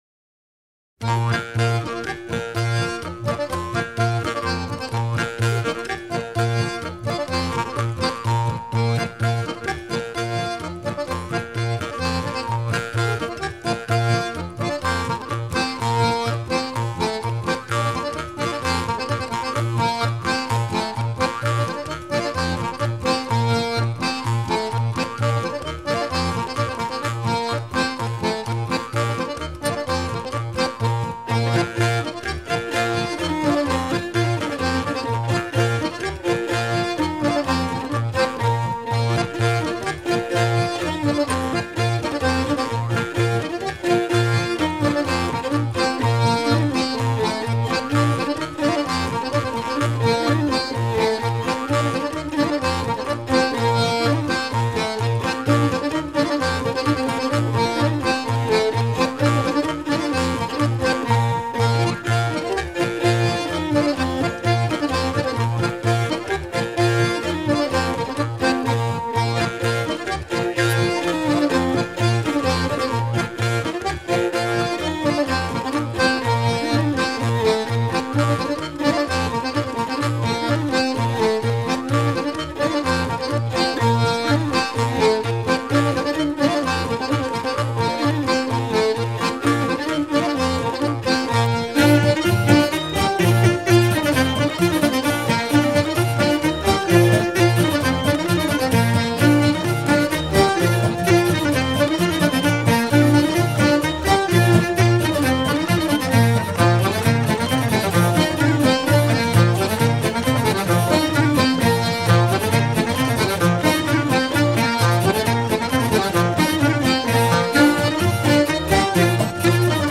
gestuel : à ramer
circonstance : maritimes
Genre énumérative
Pièce musicale éditée